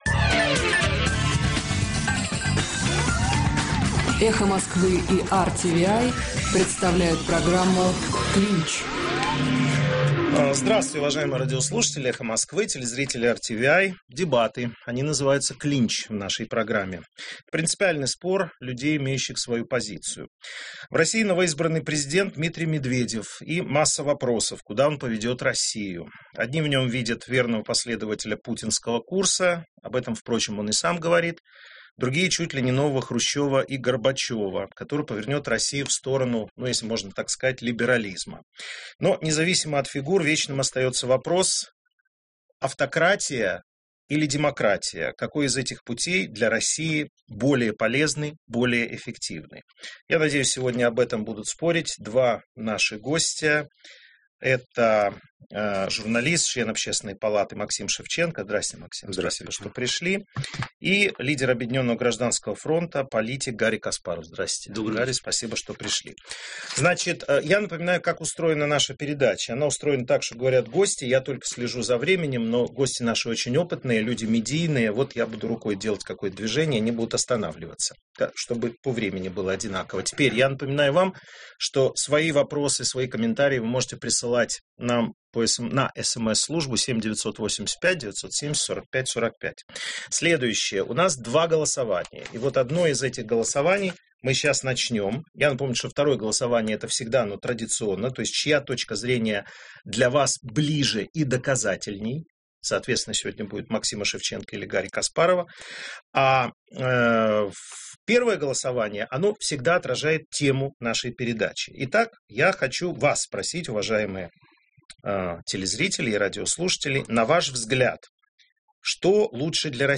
Дебаты.